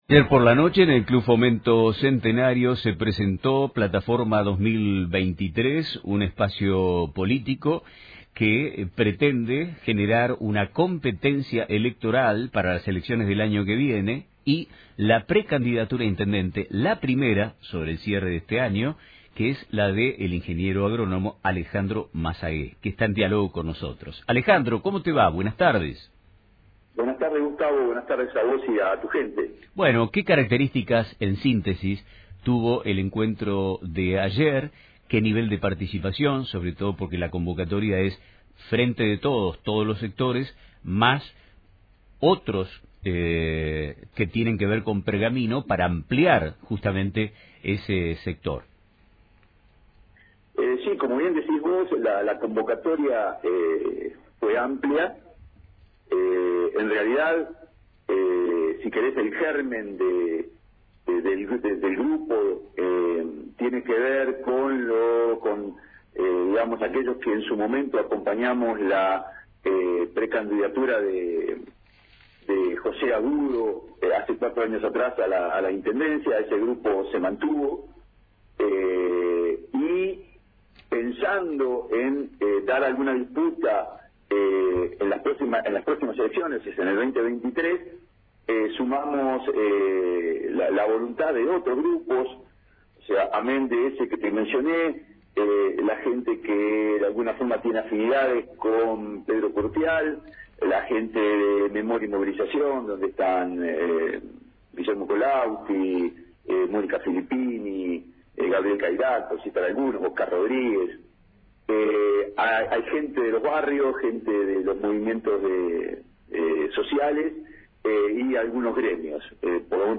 en diálogo con Nuestro Tiempo de Radio